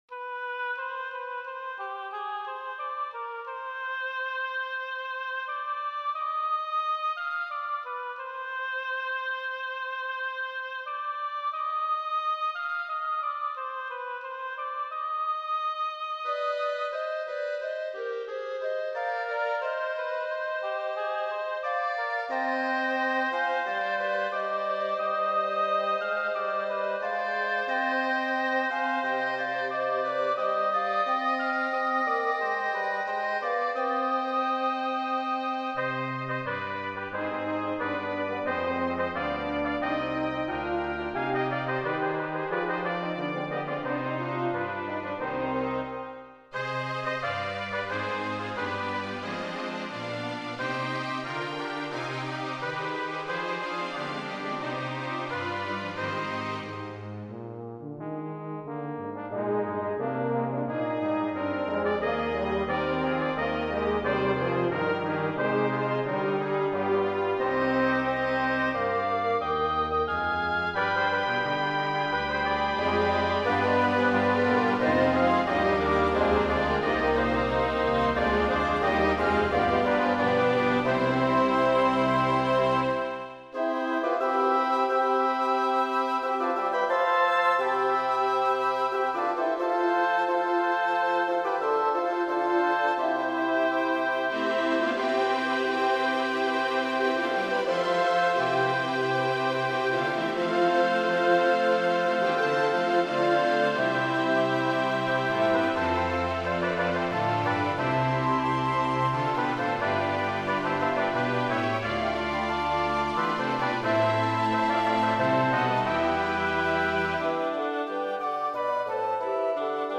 Roy Howard has composed a new work for orchestra that matches the theme of a 2008 GPAC gallery exhibit.
Flute, Soprano Recorder, Alto Recorder, Bb Clarinet, Oboe, Bassoon
Trumpets 1,2; French Horn, Trombone, Tuba
Violins 1,2; Viola, Cello, Bass